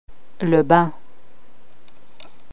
pronunciation-fr-le-bain.mp3